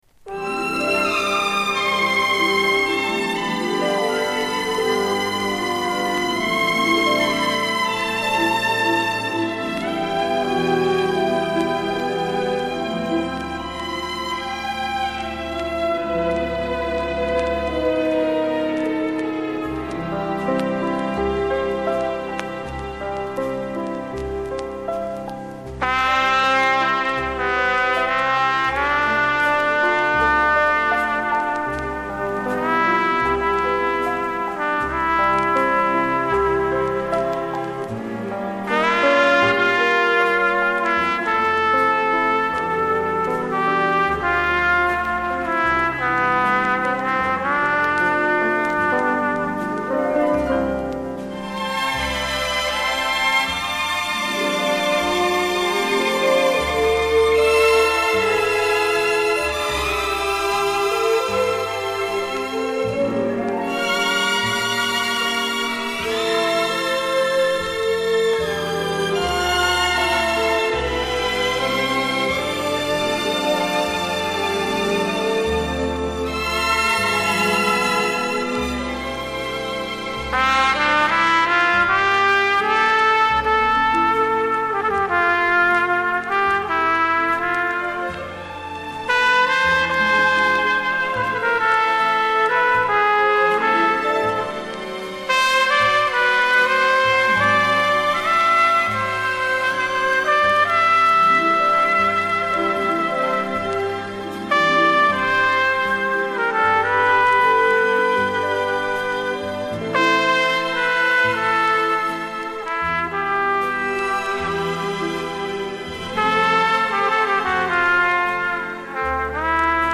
в стиле "ballada" со струнным оркестром